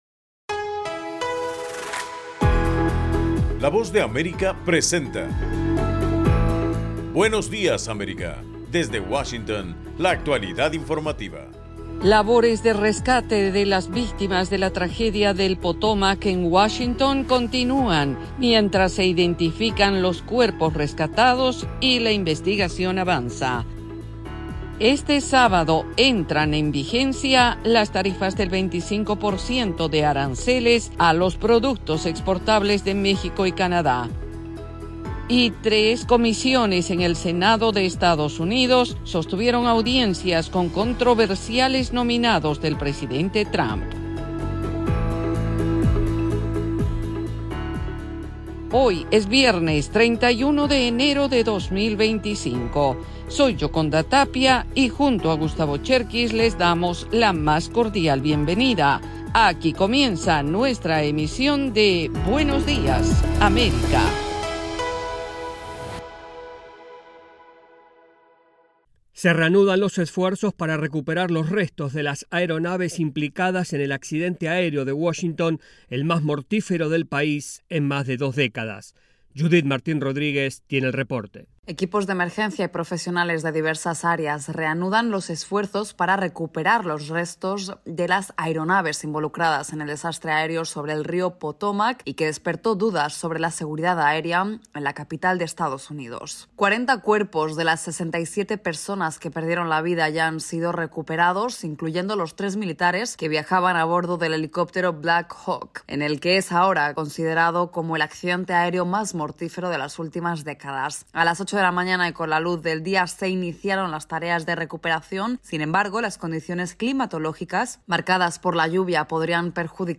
Labores de rescate de las víctimas de la tragedia del Potomac en Washington continúan mientras se identifican los cuerpos rescatados y la investigación avanza. Esta y otras noticias de Estados Unidos y América Latina en Buenos Días América, un programa de la Voz de América.